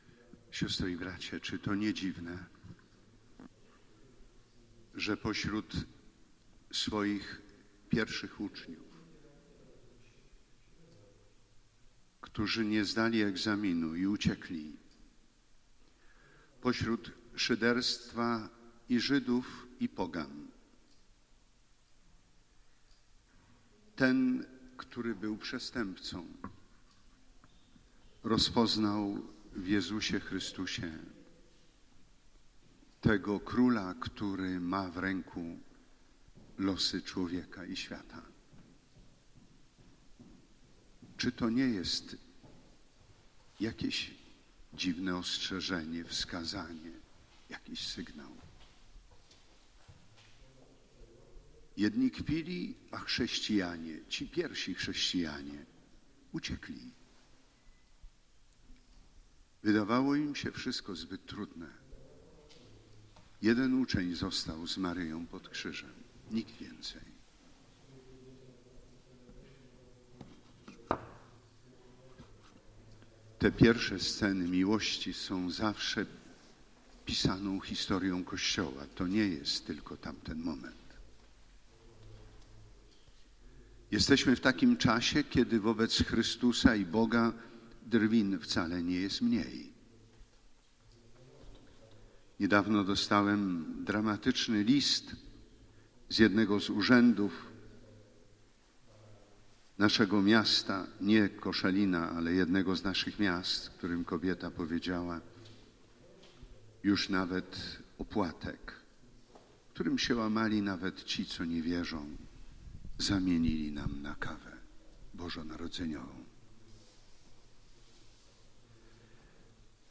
Homilia bp. Edwarda Dajczaka, który 20 listopada 2016 przewodniczył Mszy św. w katedrze na zakończenie Roku Miłosierdzia.
Homilia bp. Edwarda Dajczaka 20 listopada 2016